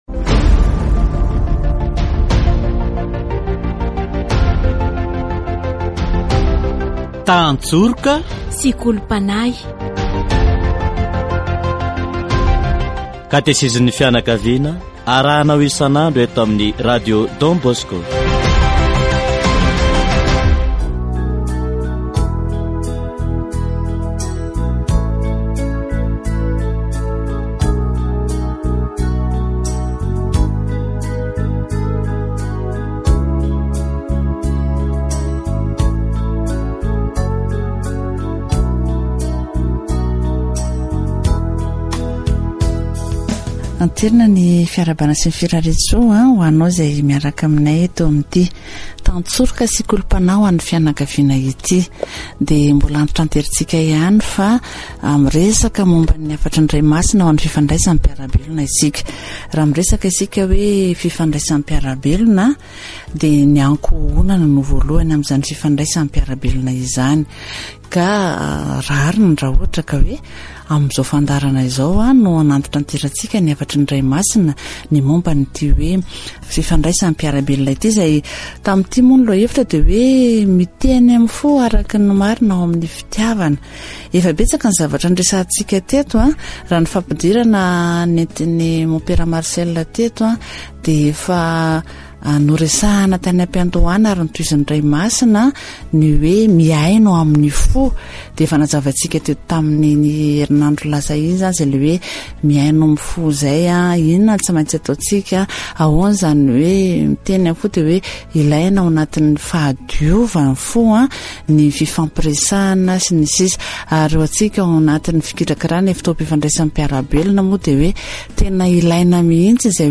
Saint François de la Sales a été choisi par l'Église comme protecteur des journalistes catholiques. Catéchèse sur la journée mondiale pour la communication sociale